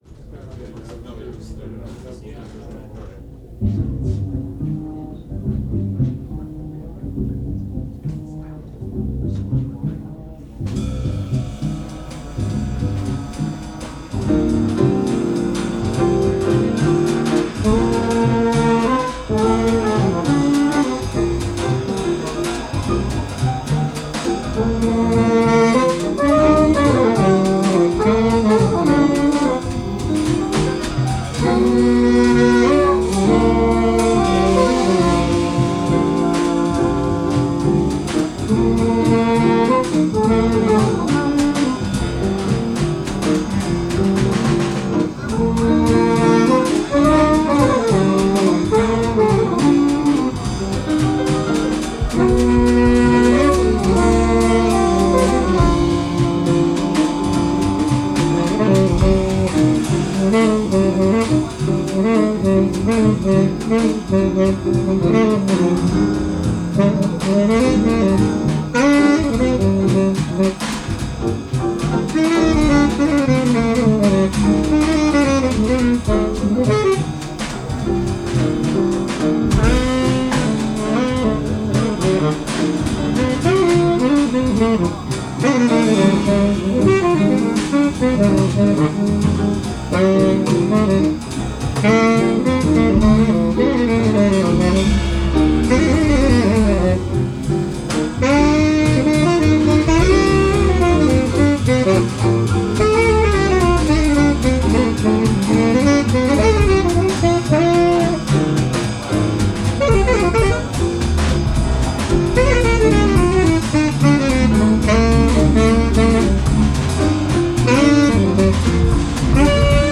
Solos by tenor sax and trumpet
Live at the Blind Pig, 1983